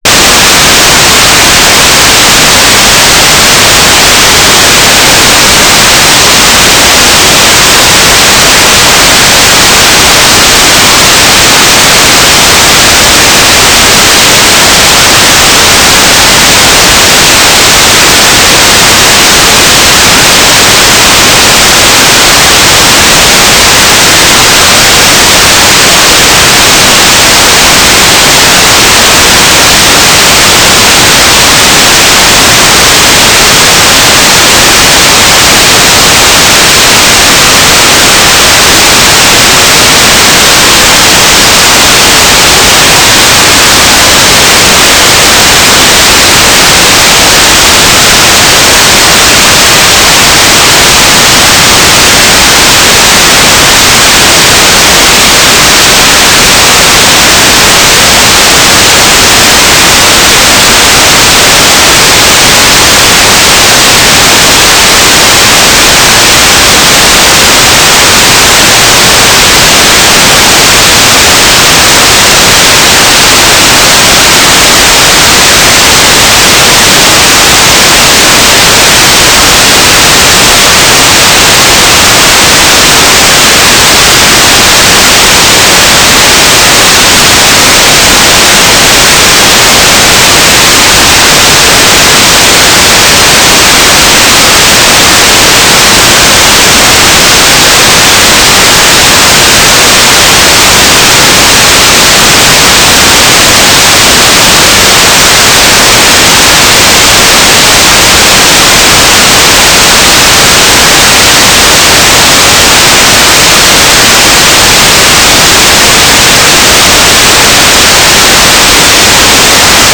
"waterfall_status": "without-signal",
"transmitter_mode": "FSK",